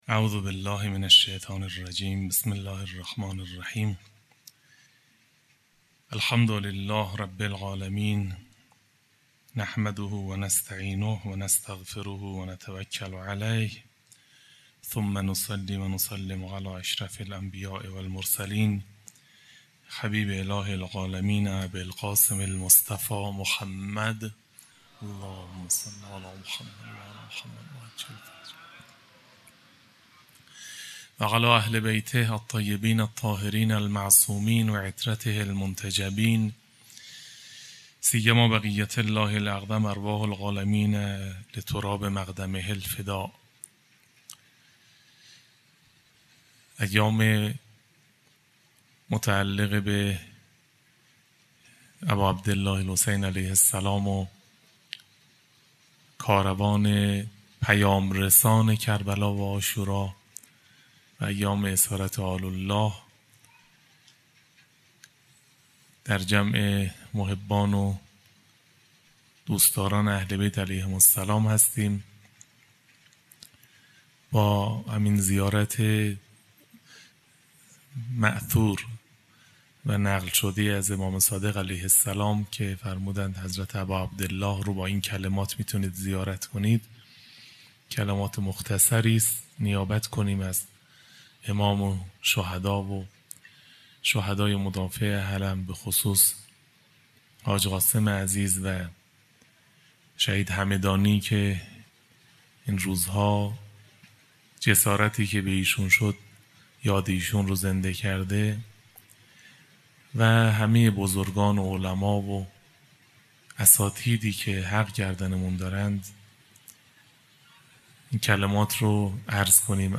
سخنرانی: خرج شدن در راه باطل، هزینه یاری نکردن حق